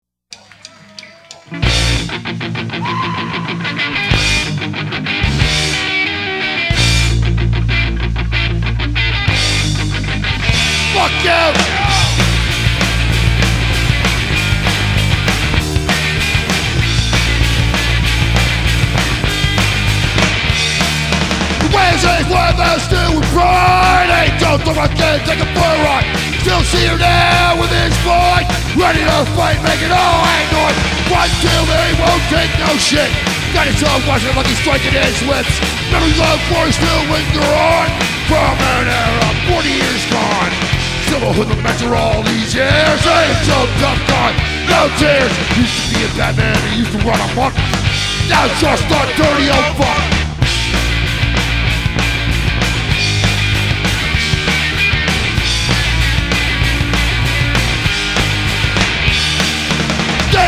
unreleased skin and street punk pummel from